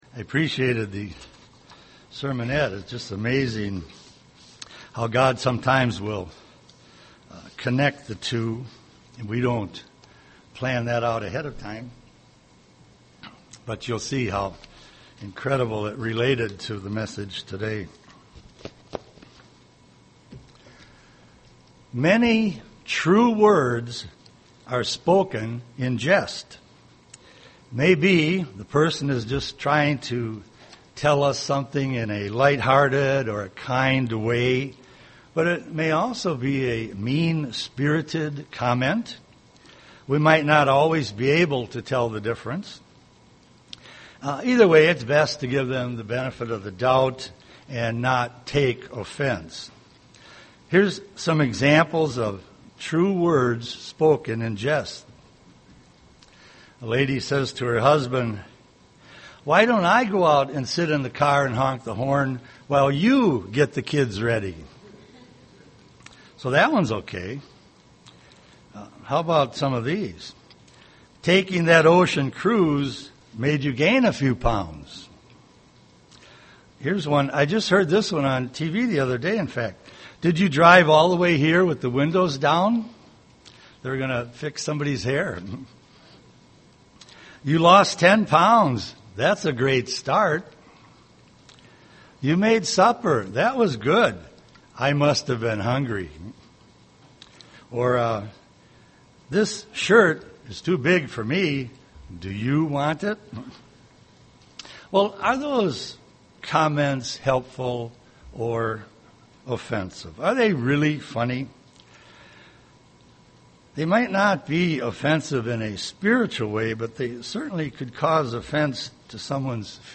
This sermon examines three potential areas where we could cause spiritual offence.
Given in Twin Cities, MN